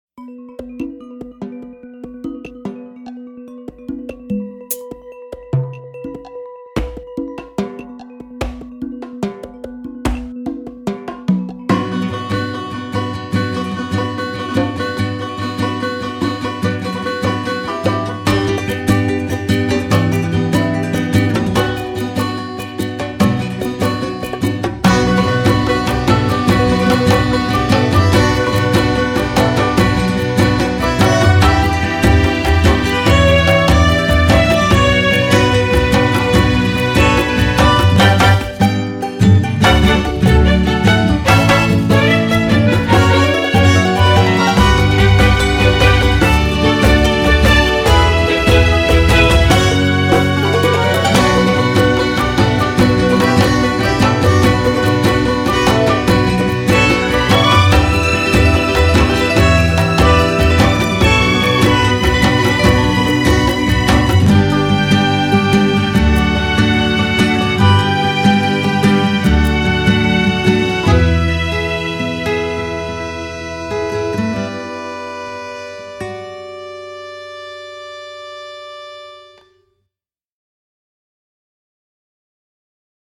此外,也编写了如教堂圣乐般的和声,表现影片中主角内心的救赎与毁灭。